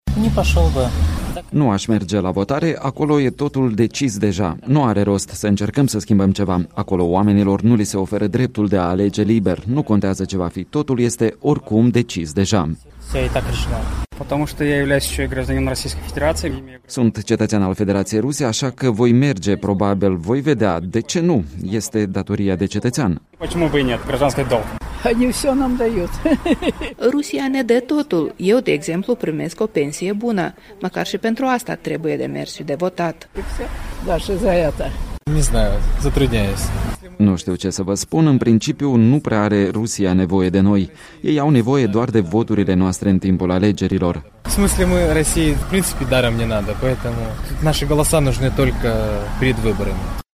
Voci din Tiraspol și Bender/Tighina despre alegerile pentru Duma de stat din Rusia